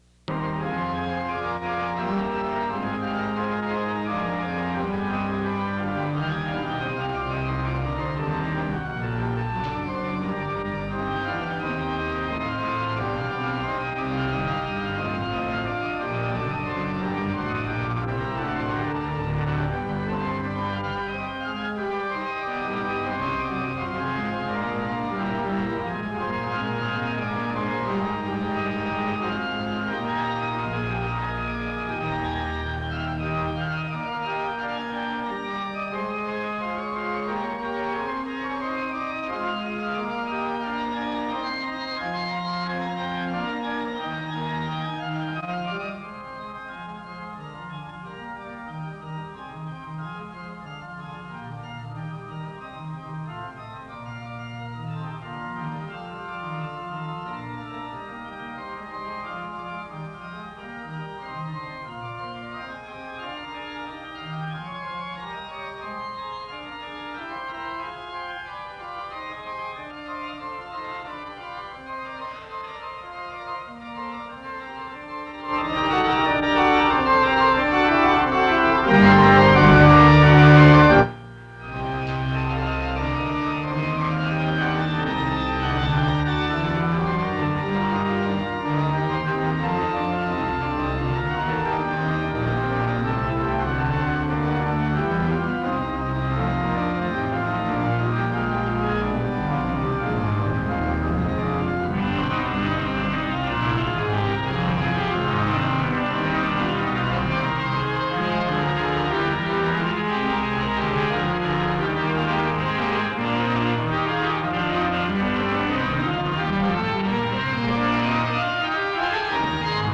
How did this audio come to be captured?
SEBTS Chapel - Student Testimonies September 20, 1978